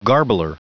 Prononciation du mot garbler en anglais (fichier audio)
Prononciation du mot : garbler